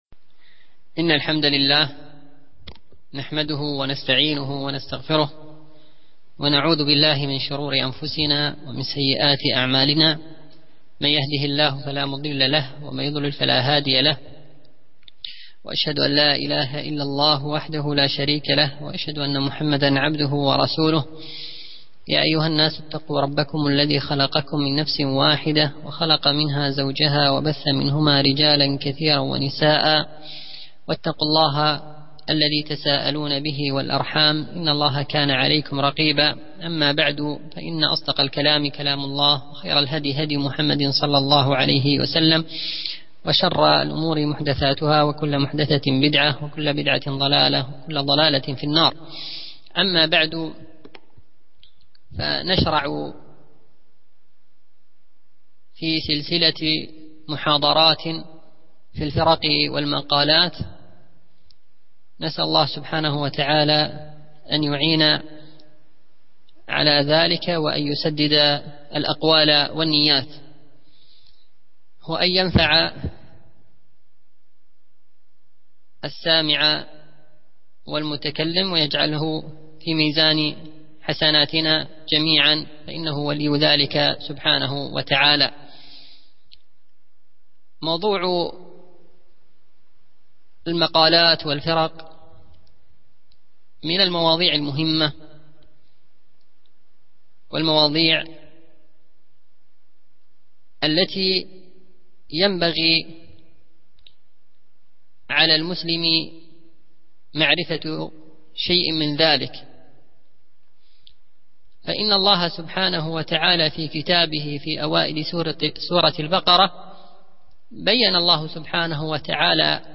السلسلة: محاضرات في الفرق و المقالات